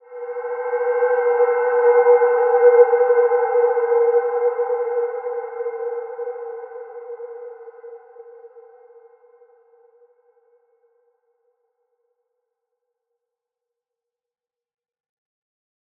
Wide-Dimension-B3-f.wav